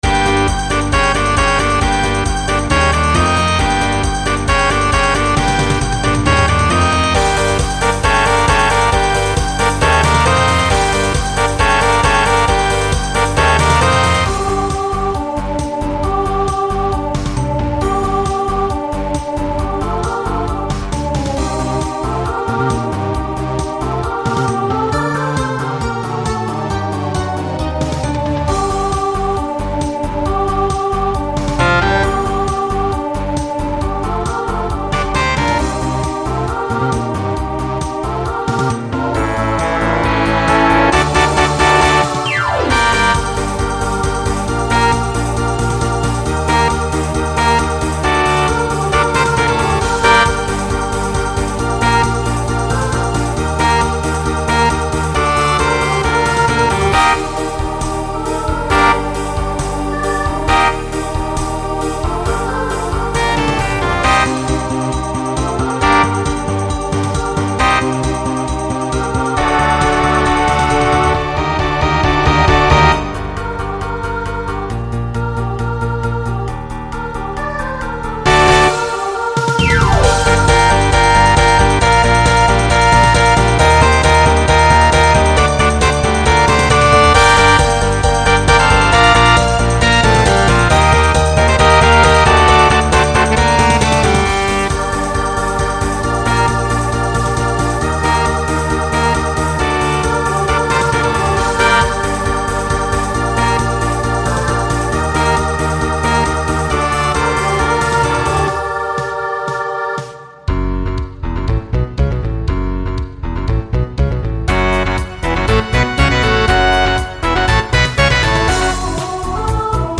SSA/TTB
Voicing Mixed
Genre Pop/Dance